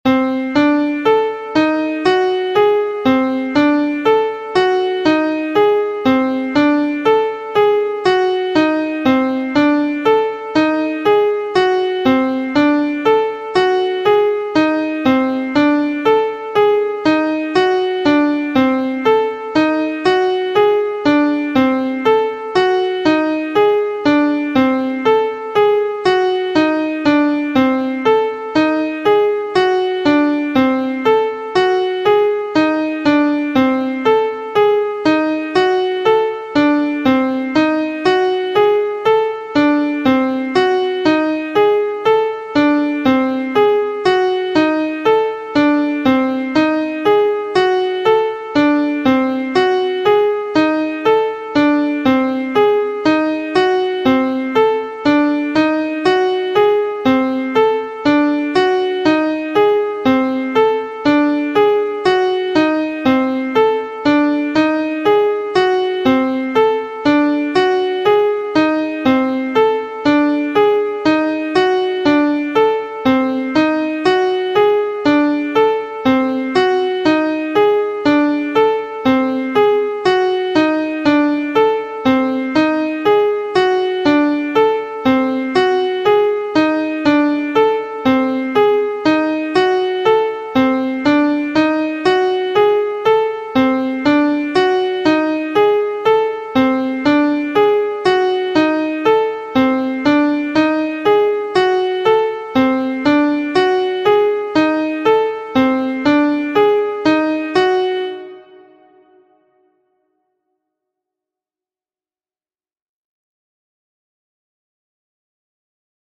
Closed Position Exercise